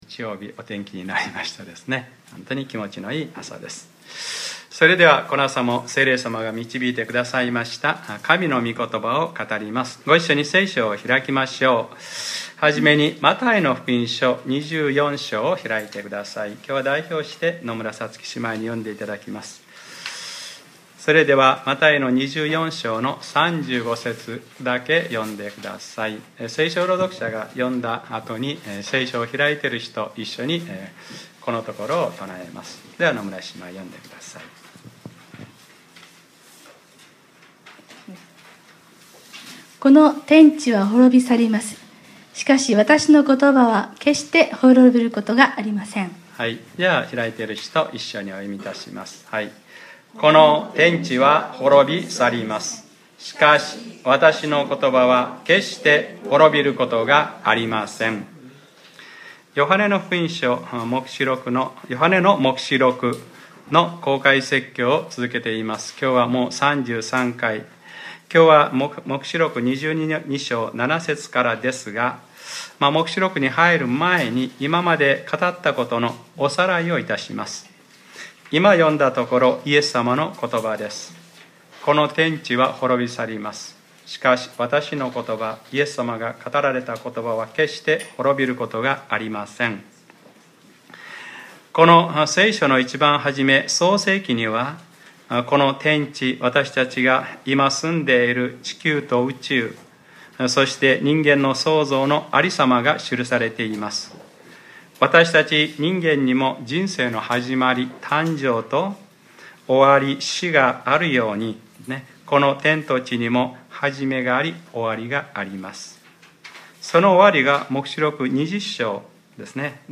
2014年8月31日（日）礼拝説教 『黙示録ｰ３３：最後の審判（白い御座の裁き）』